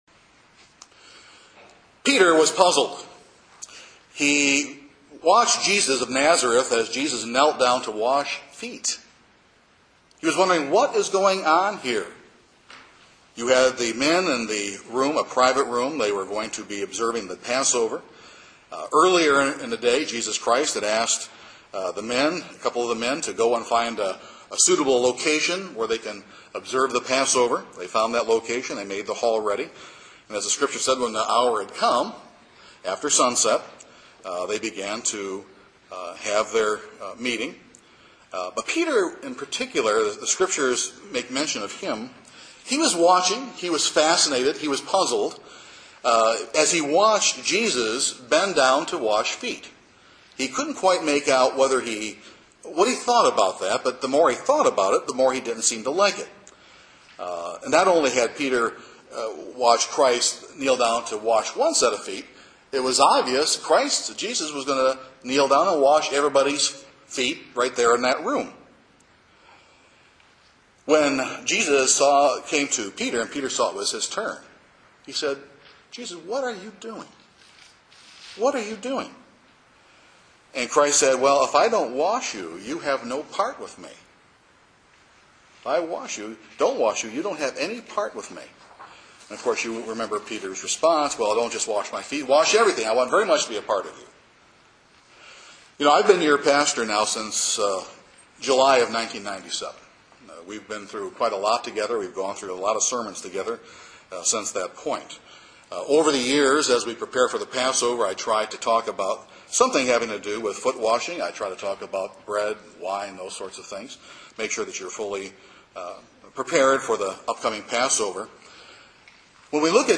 This sermon explores that concept.